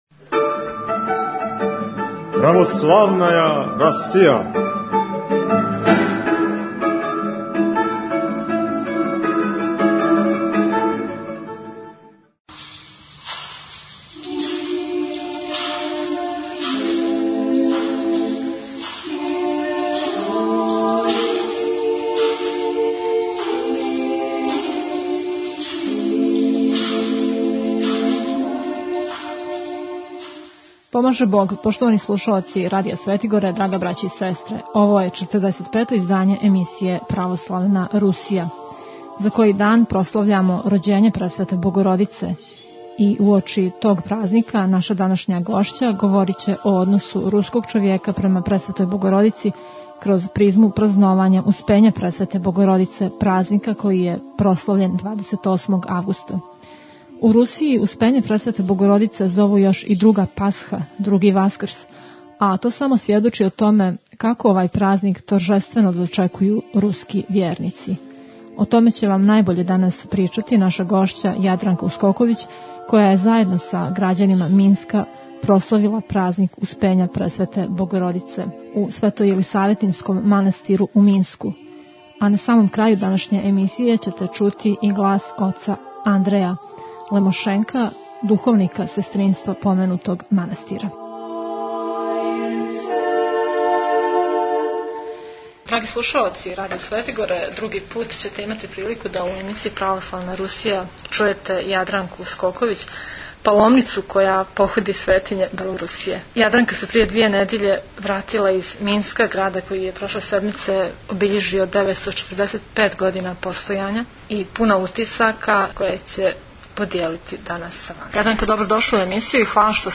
кратку бесједу